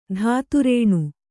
♪ dhātu rēṇu